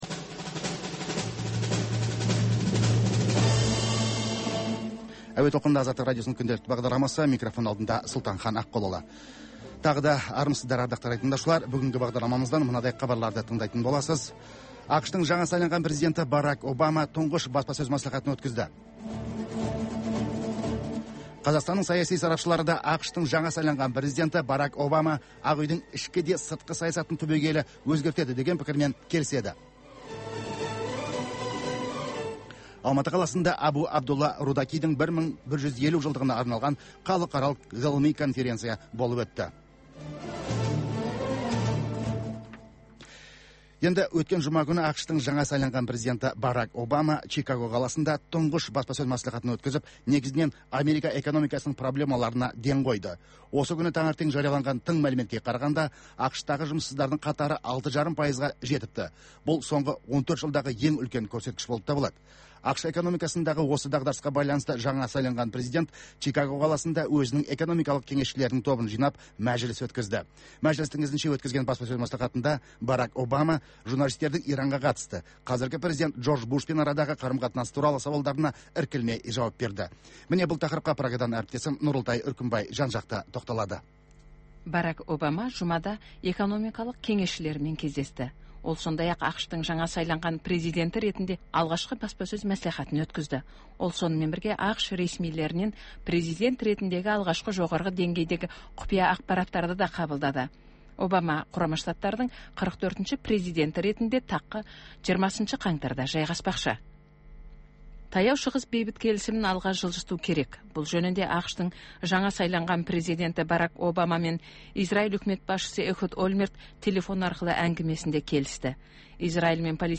Бүгінгі күннің өзекті мәселесі, пікірталас, оқиға ортасынан алынған репортаж, қазақстандық және халықаралық сарапшылар пікірі, баспасөзге шолу.